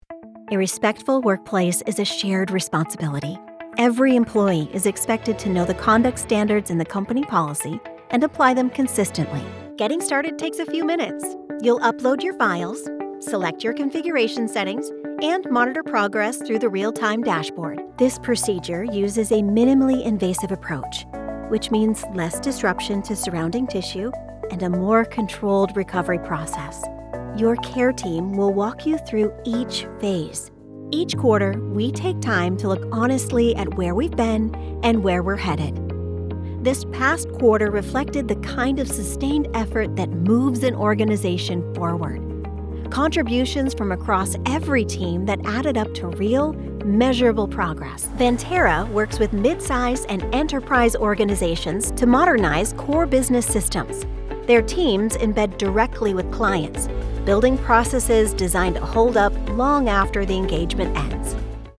Never any Artificial Voices used, unlike other sites.
E=learning, Corporate & Industrial Voice Overs
Adult (30-50) | Yng Adult (18-29)